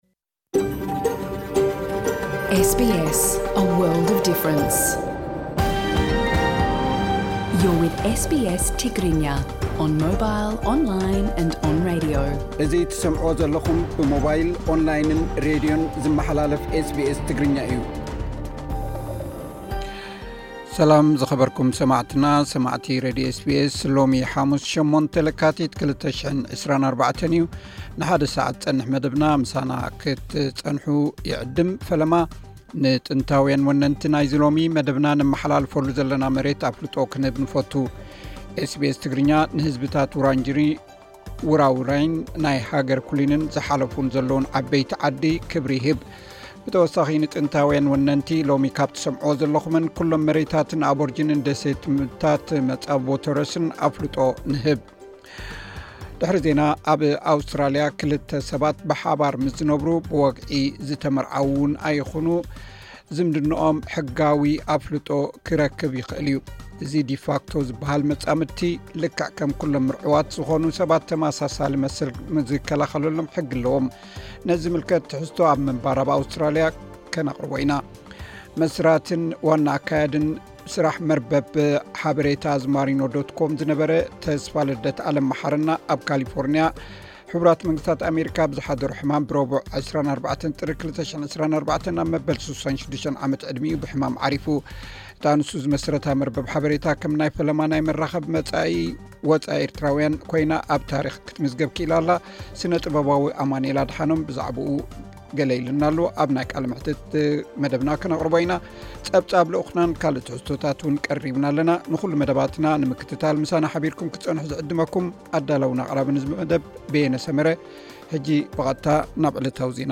ቀጥታ ምሉእ ትሕዝቶ ኤስ ቢ ኤስ ትግርኛ (08 ለካቲት 2024)
ኣብ ናይ ቃለ መሕትት መደብና ክቐርብ እዩ።